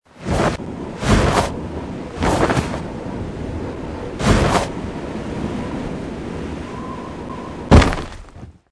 JQ_tiaoyue_windy.mp3